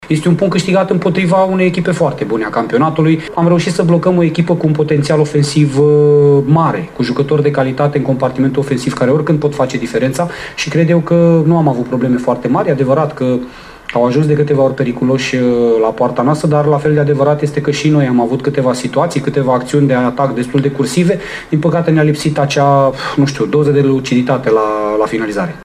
După încheierea întâlnirii, antrenorul gazdelor, Laszlo Balint, s-a bucurat de această realizare minimă, pe care o vede însă ca punct de plecare pentru clădirea moralului ulterior: